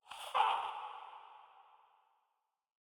Minecraft Version Minecraft Version 1.21.4 Latest Release | Latest Snapshot 1.21.4 / assets / minecraft / sounds / enchant / soulspeed / soulspeed3.ogg Compare With Compare With Latest Release | Latest Snapshot